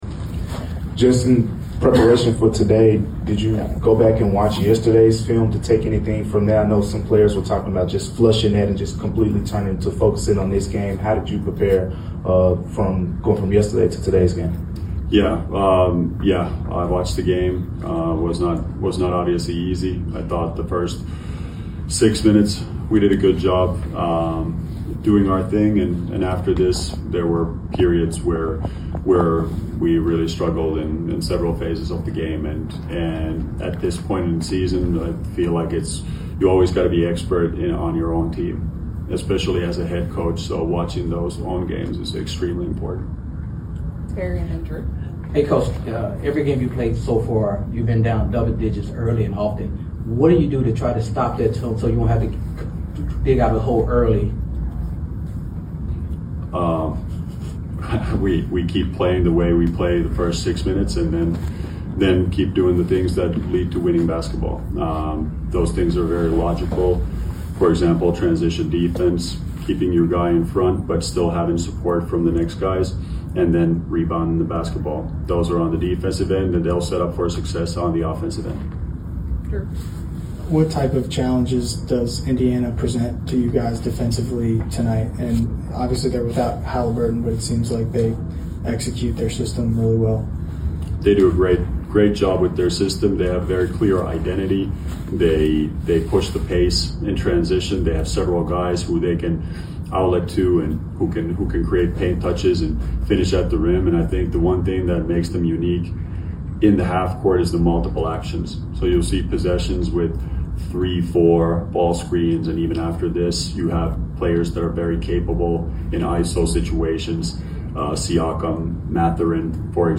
01-30-25 (Bossman Show) | Will Wade Interview | Bossman Show